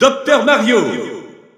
French Announcer announcing Dr. Mario.
Dr._Mario_French_Announcer_SSBU.wav